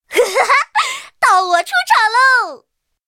M4A3E2小飞象开火语音1.OGG